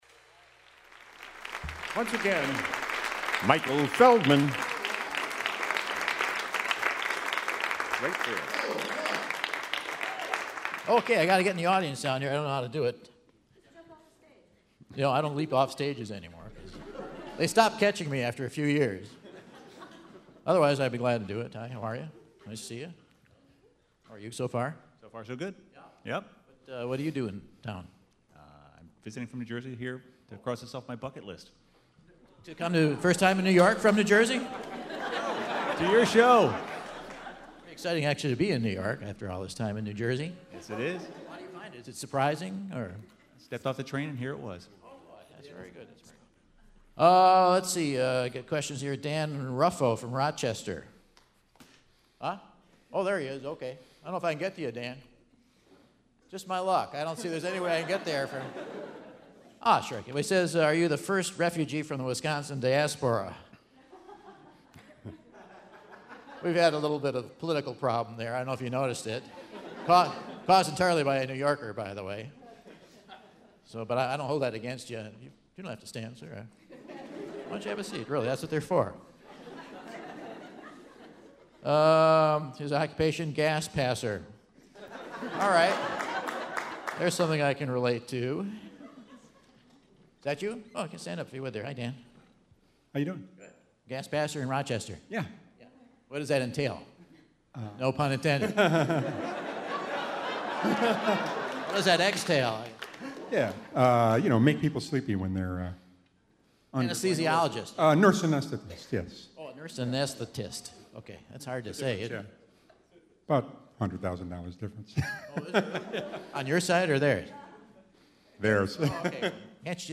Quiz A - June 9, 2012 in NYC
A cross generational team plays the Whad'Ya Know? Quiz!